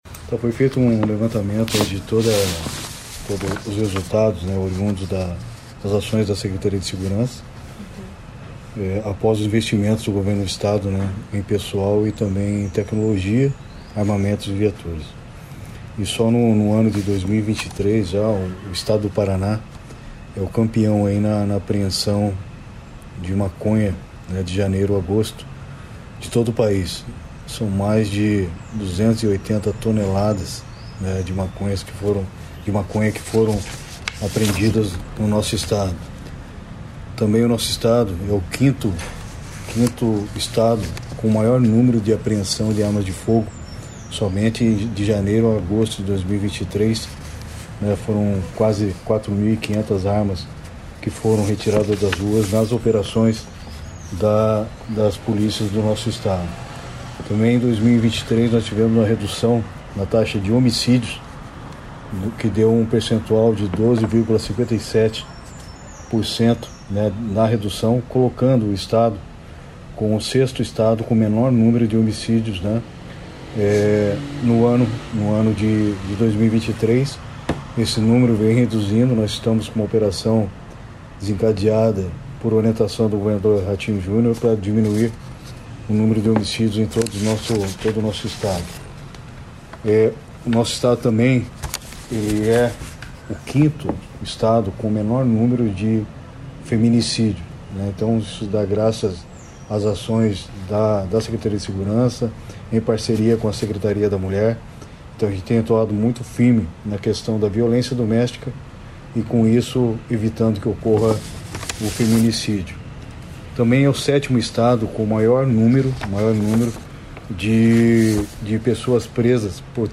Sonora do secretário Estadual de Segurança Pública, Hudson Teixeira, sobre a liderança do Paraná nas apreensões de maconha no país em 2023